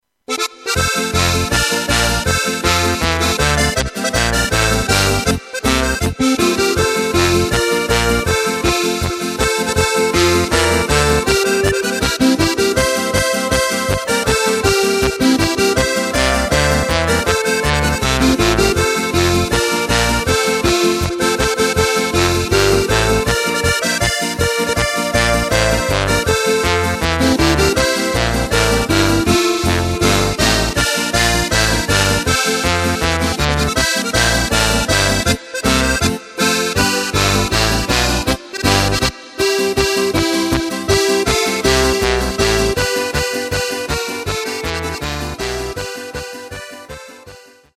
Takt:          2/4
Tempo:         160.00
Tonart:            F
Flotte Polka aus dem Jahr 2020!